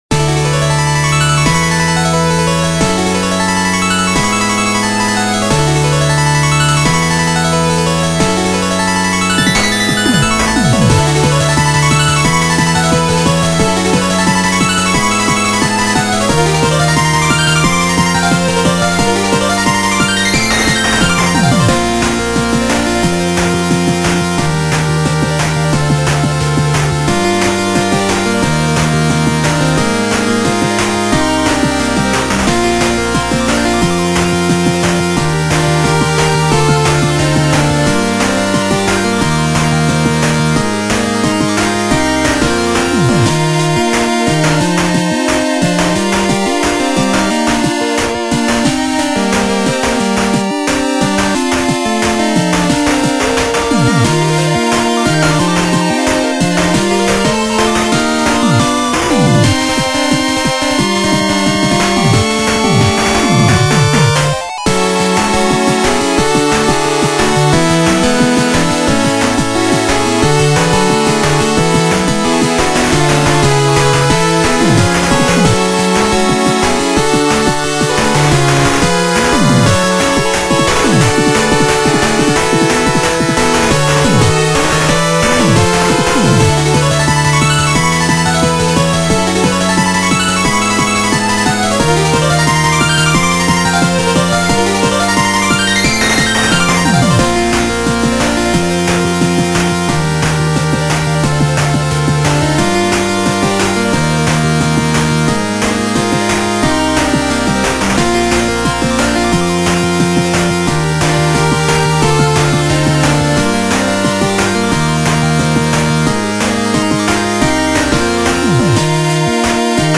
ジャンル8-Bit
使用例ボス戦闘曲、躍動感のある動画、感動
BPM１７８
使用楽器8-Bit音源
原曲同様の疾走感でコンテンツを彩りましょう。
ファミコン風(8-Bit music)